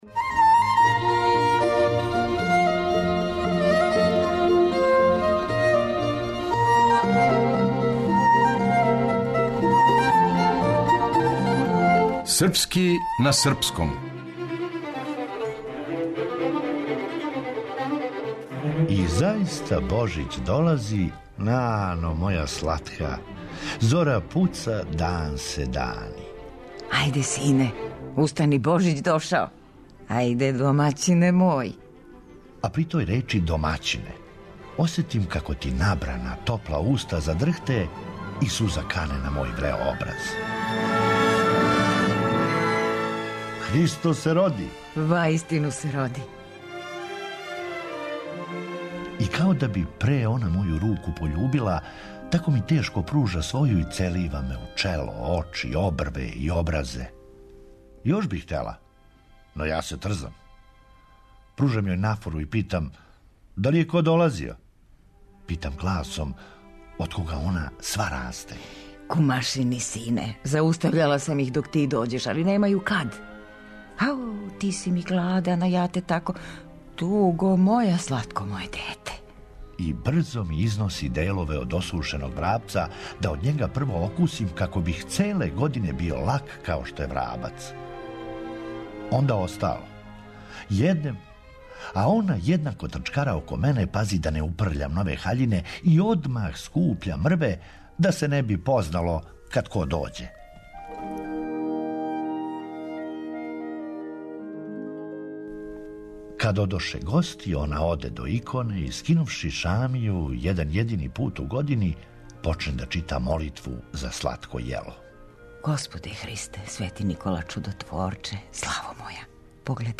(Драматизација одломка из приповетке Борисава Станковића - "Наш Божић")